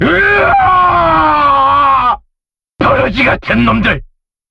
limbus company gregor ff 3 Meme Sound Effect